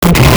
Player_Glitch [1].wav